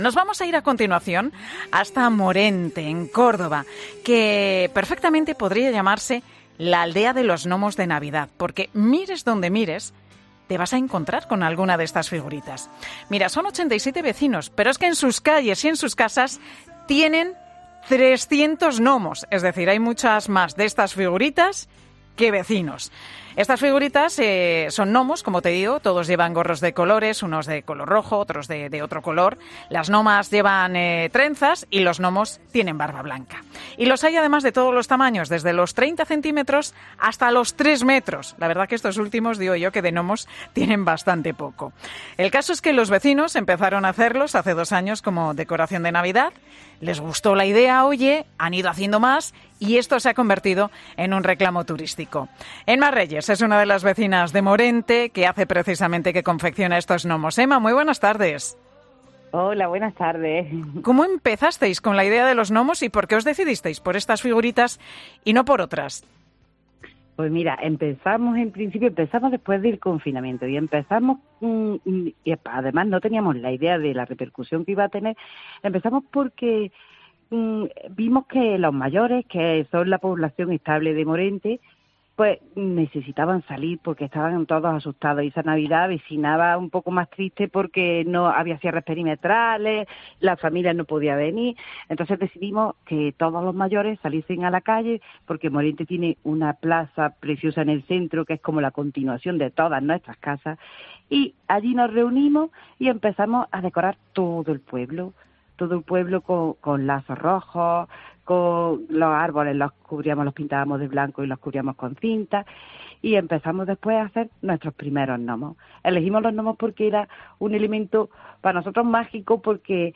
18:00 H | 5 SEP 2025 | BOLETÍN